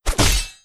Heavy_Sword1.wav